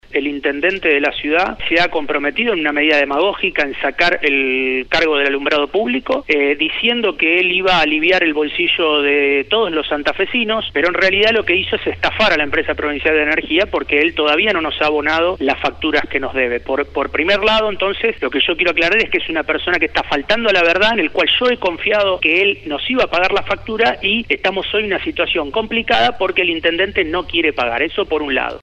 El Presidente de la Empresa Provincial de la Energía, Maximiliano Neri realizó un fuerte descargo a través de Radio EME tras la filtración de los supuestos sueldos que cobran los directivos de la distribuidora.